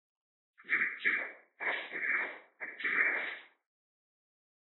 Got a Russian friend (guy who helps us with English->Russian localization) to record some fun voice lines.